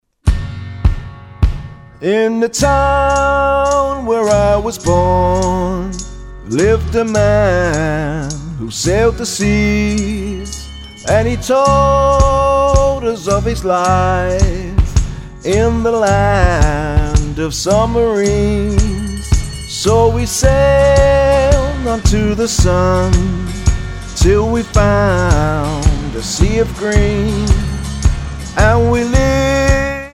Tonart:F# Multifile (kein Sofortdownload.
Die besten Playbacks Instrumentals und Karaoke Versionen .